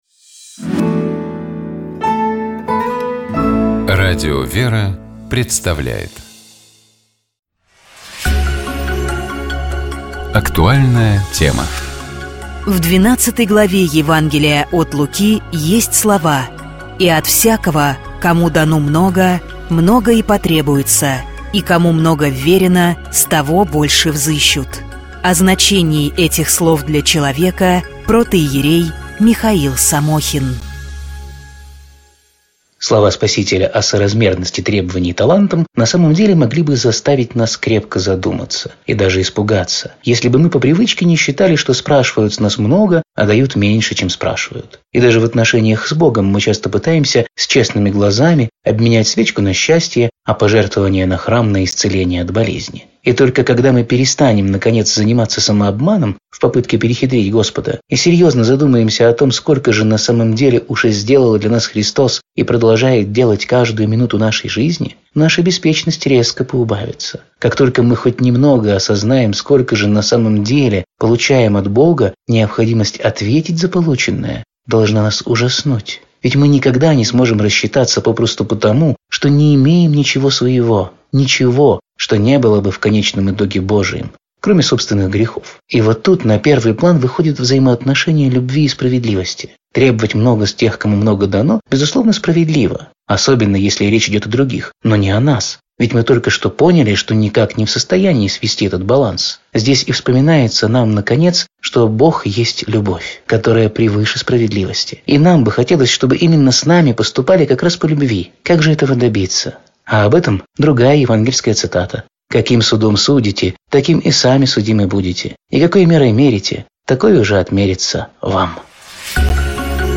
В воскресенье, 17 ноября, Святейший Патриарх Московский и всея Руси Кирилл совершил Божественную литургию в Храме Христа Спасителя в Москве.
На проповеди по завершении Литургии Предстоятель Русской Православной Церкви говорил о спасении человека по благодати Божьей: